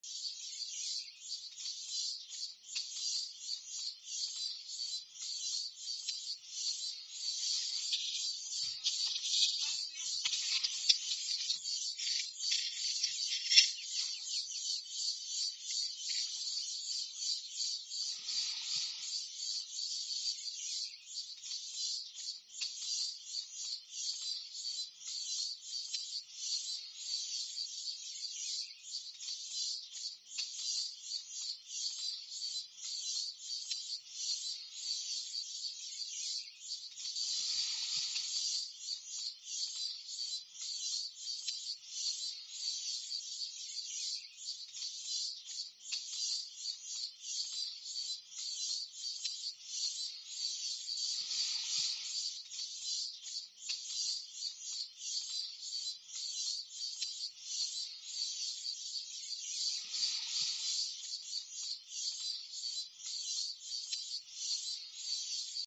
Som ambiente do Pantanal Marimbus.mp3